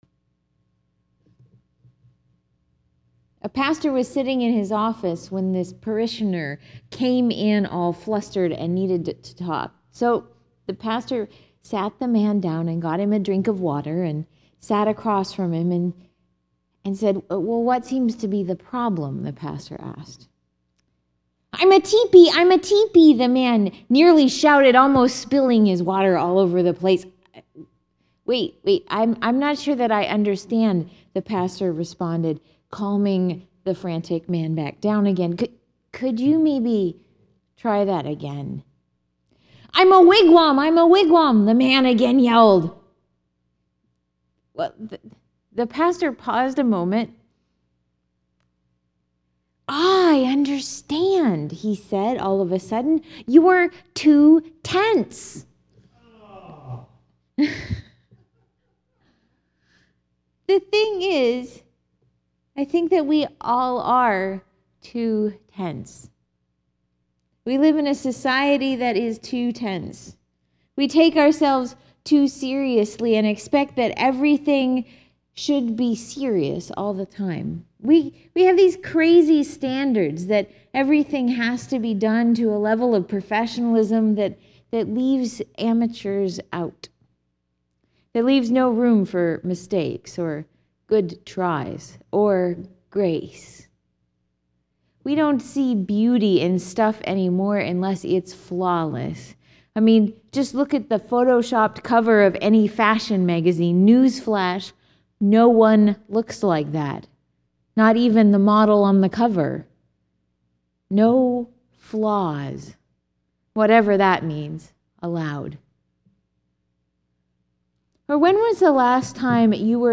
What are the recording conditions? The texts for Sunday, April 15, 2012 (Holy Humour Sunday): Acts 4:32–35; Psalm 133; 1 John 1:1—2:2; John 20:19–31